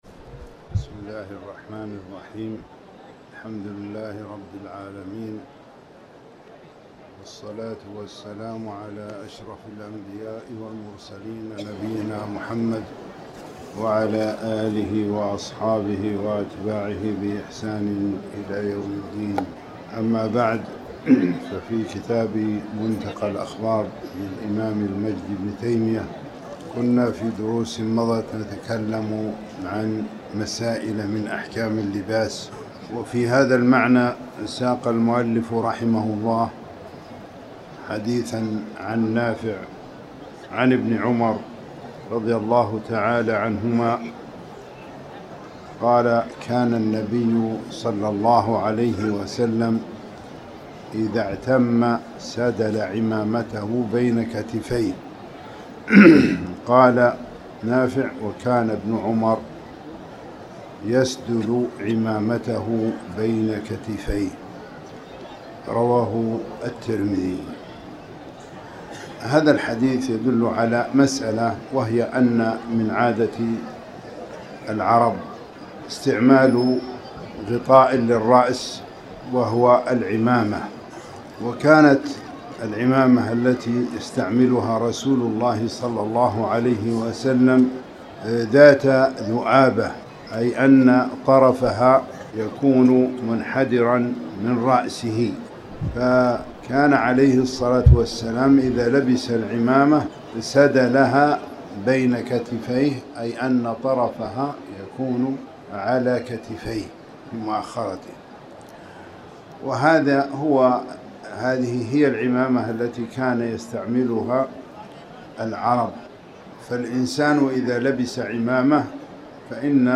تاريخ النشر ١٦ جمادى الأولى ١٤٤٠ هـ المكان: المسجد الحرام الشيخ